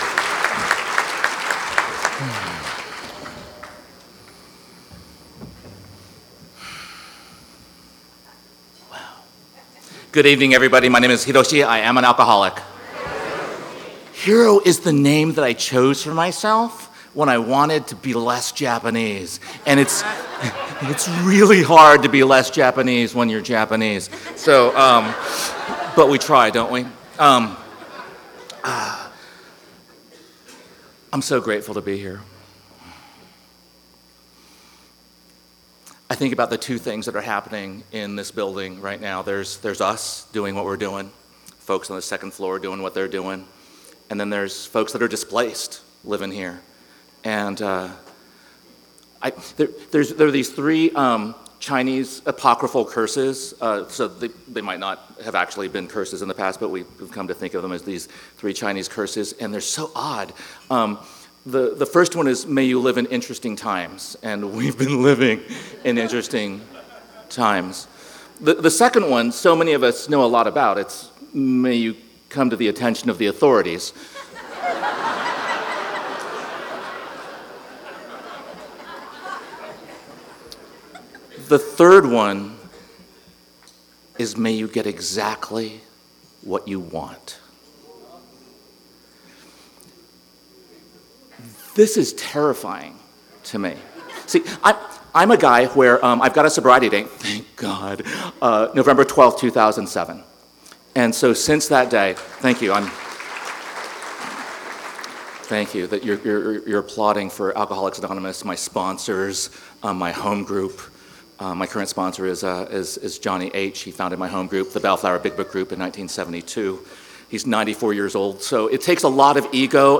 49th San Fernando Valley Alcoholics Anonymous Convention